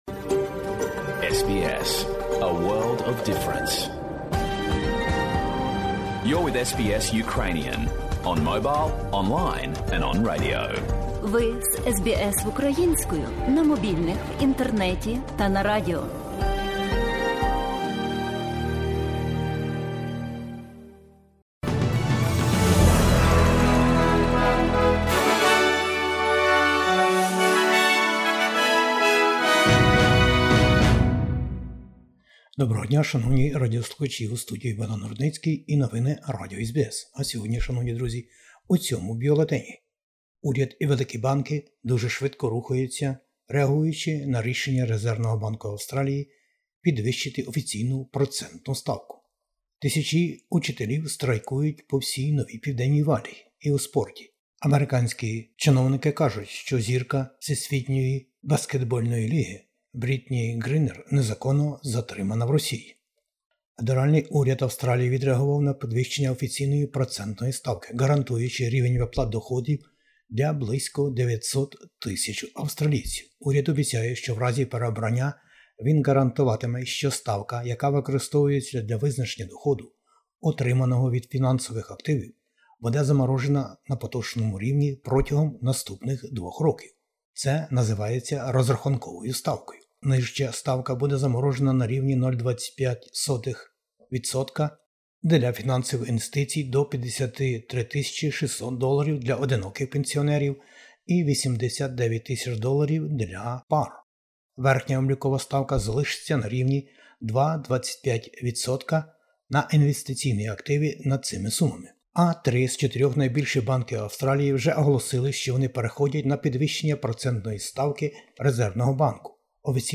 Бюлетень SBS новин українською. Федеральні вибори-2022 уже 21-го травня. Резервний Банк Австралії про те, чому треба було підвищити процентну ставку. Прем'єр Британії звернувся до Верховної Ради України.